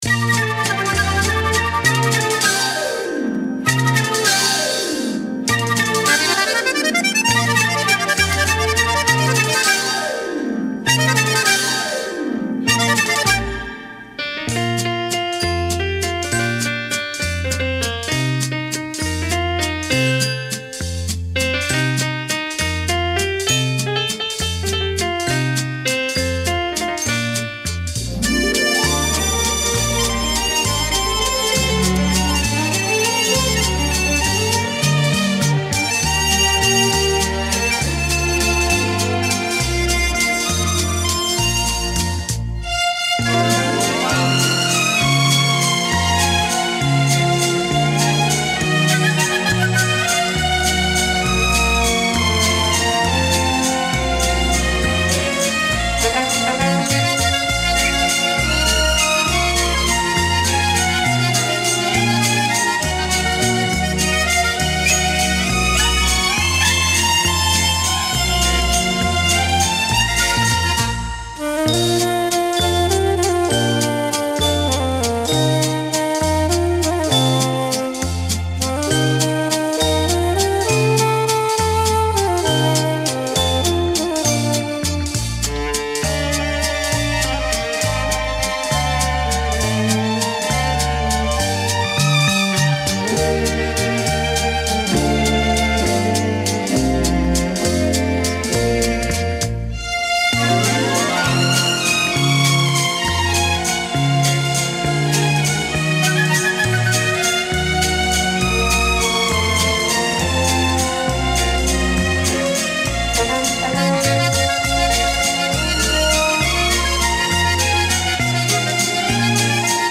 Py:Stereo